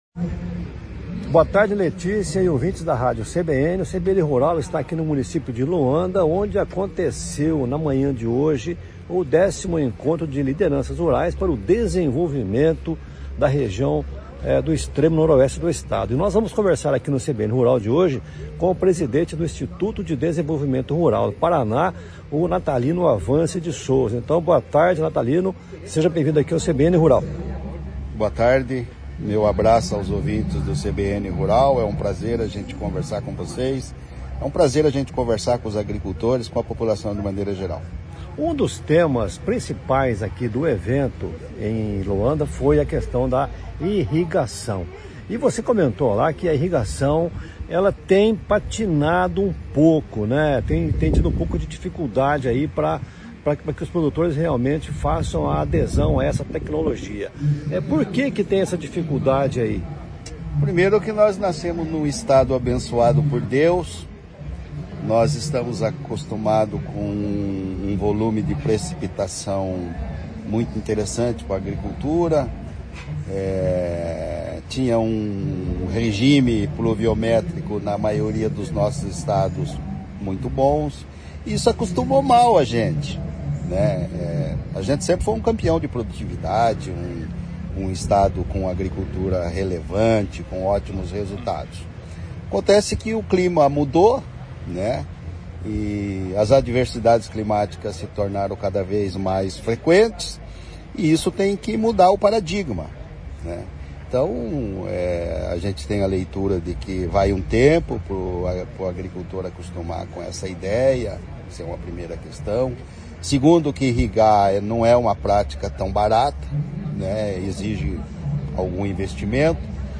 O CBN Rural esteve nesta sexta-feira em Loanda, no noroeste do estado, onde acompanhou o 10o Encontro de Lideranças para o Desenvolvimento da Região. Lá, conversou com o presidente do Instituto de Desenvolvimento Rural do Paraná, Natalino Avance de Souza, para quem a irrigação é a solução visando a potencializar a agropecuária e gerar riquezas.